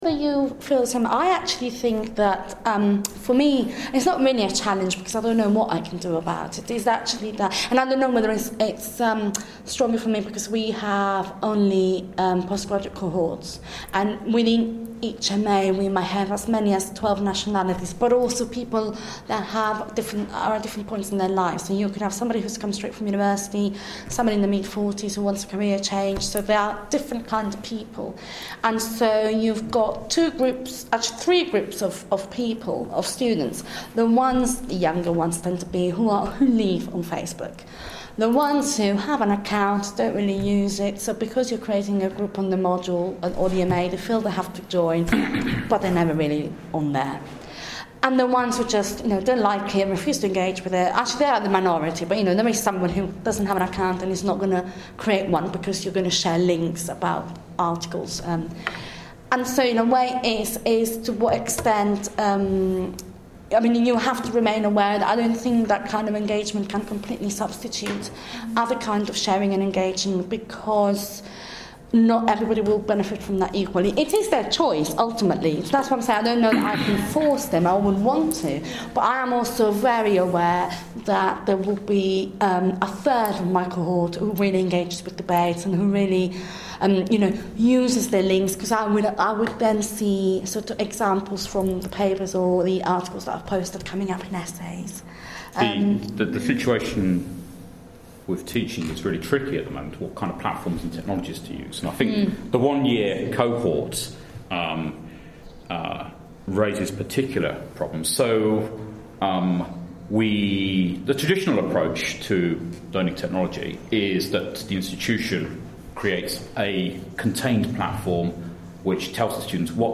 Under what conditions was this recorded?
The panel (below) responds at this Digital Change GPP event earlier in the year.